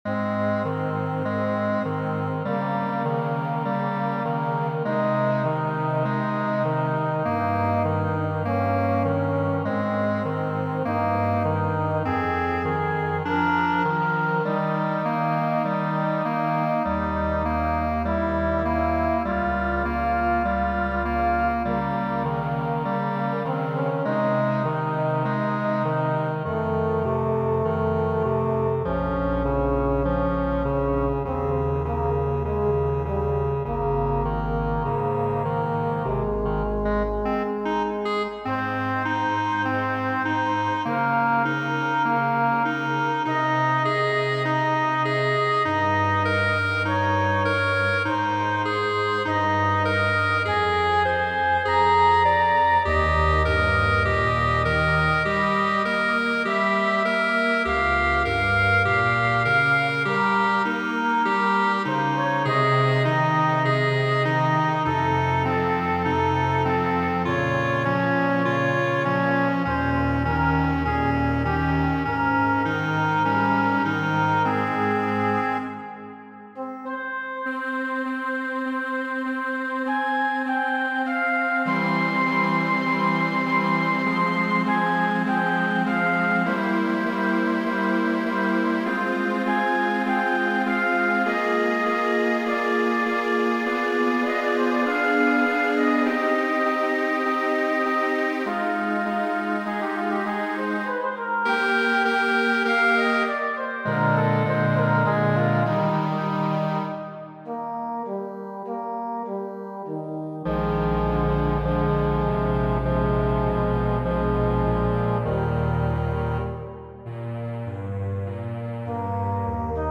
Dua parto de la sonato Patetika , de Ludoviko Beetoveno. Mi midiigis ĝin por orĥestro, ĉar mi opinias, ke piano estis nur rimedo por la aŭtoro, ne la celo.